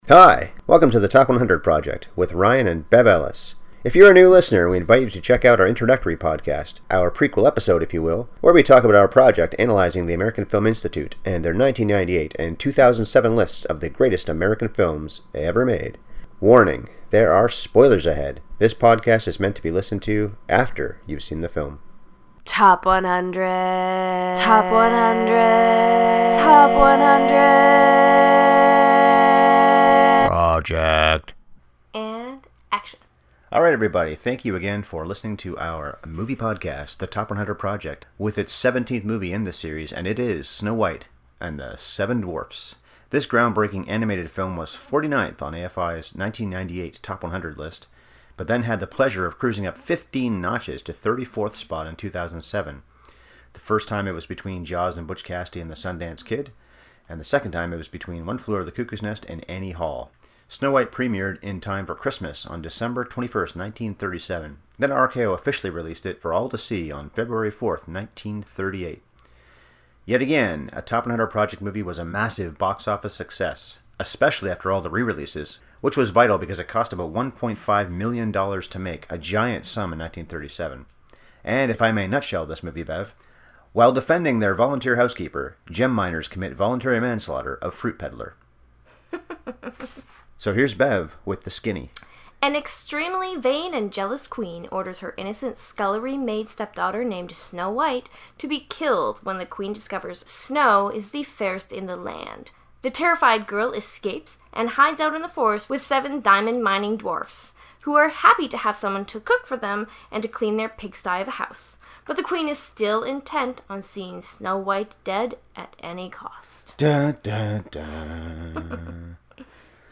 Snow White And The Seven Dwarfs (30:52) Podcast #17 finds us chatting about one of the all-time box office champs! If you want theories about evil fruit, how the Queen is the original Bond villain, and to hear us sing an impromptu ditty, then this is the ‘cast for you.